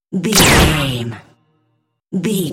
Dramatic stab laser
Sound Effects
Atonal
heavy
intense
dark
aggressive
hits